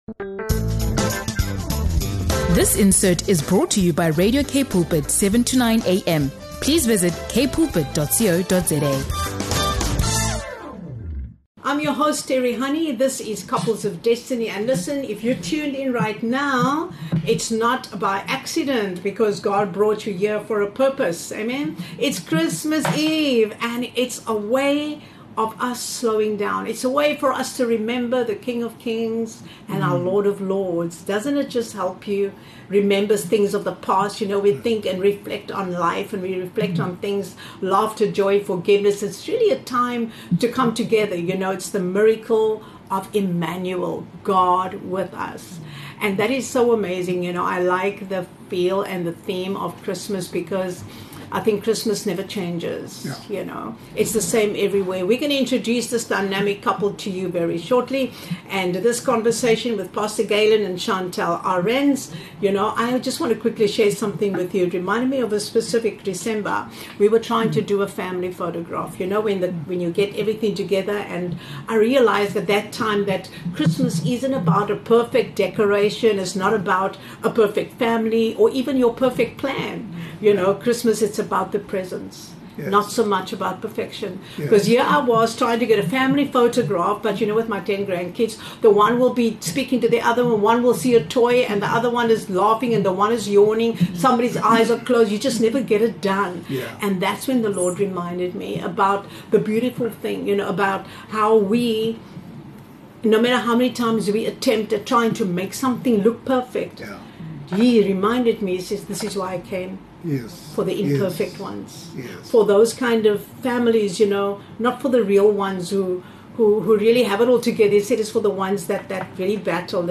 In this powerful and faith-filled conversation, we reflect on the true meaning of Christmas: Emmanuel, God with us. From imperfect family moments and messy memories to forgiveness, grace, and hope, this episode speaks to anyone whose Christmas doesn’t look like a Pinterest board—but needs Jesus just the same.